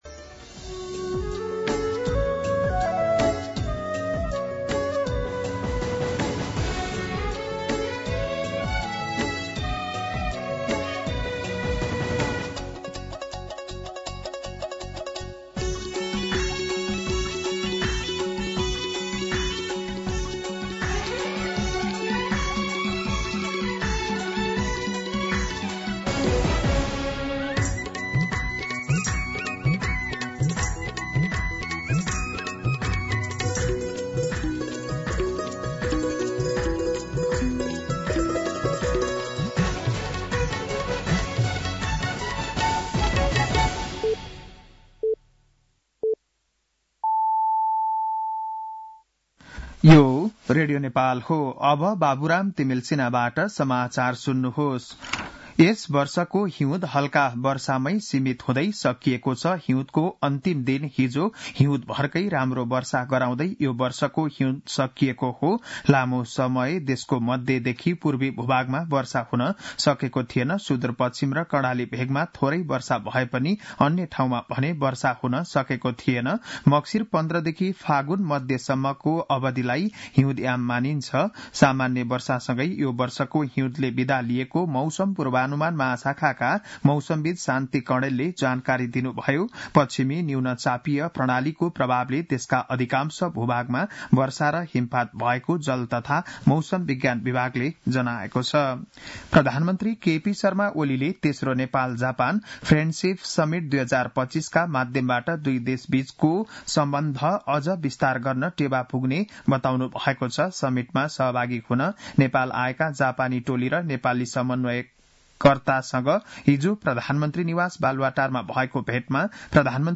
बिहान ११ बजेको नेपाली समाचार : १८ फागुन , २०८१